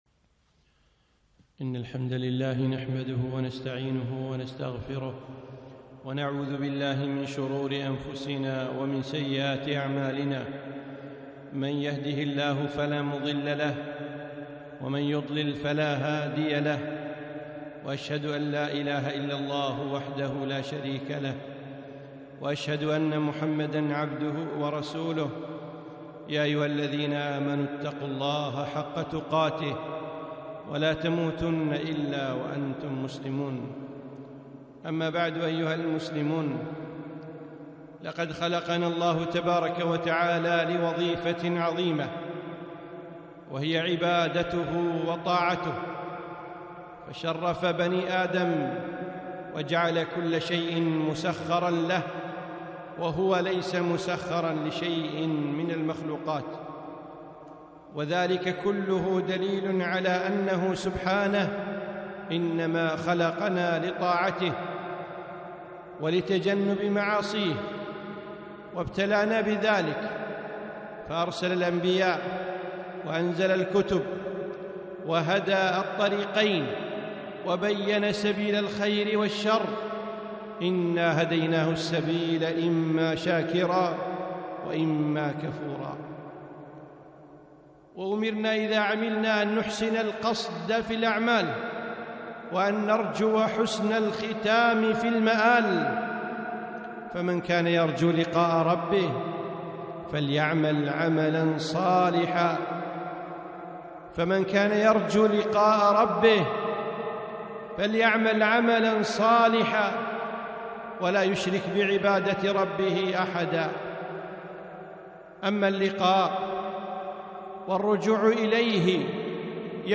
خطبة - العمل بالخواتيم